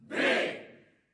监狱的门闩" 反复摔打的铁门
描述：在一个旧的监狱牢房录制
声道立体声